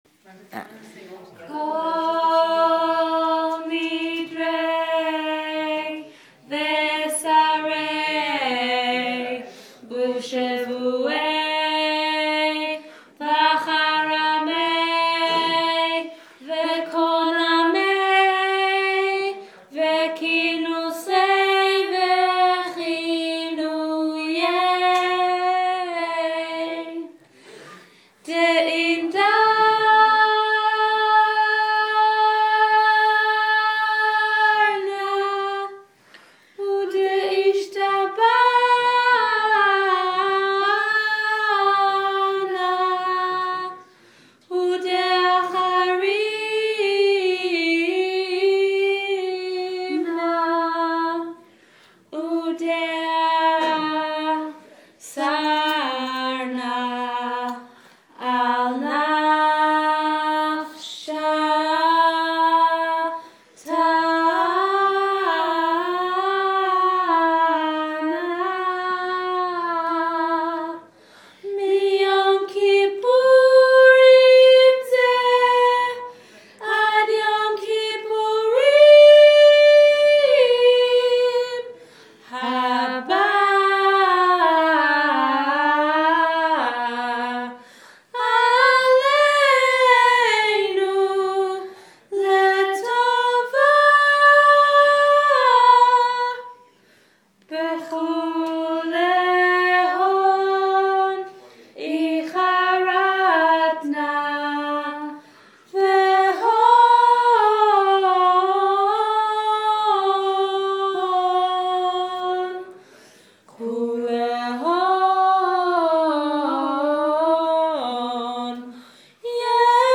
Choir preparation files for Kol Nidrei at Shira Melbourne, with separate parts and emphasised unison recordings.
Separate choir parts and emphasised unison recordings for bass, tenor, alto and soprano. Arrangement by Jonathan Skovron.
soprano.m4a